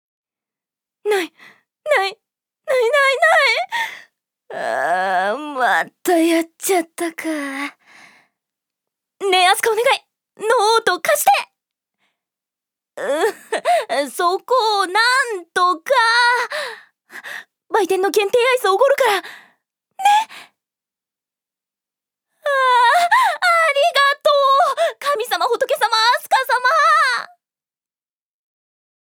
女性タレント
セリフ４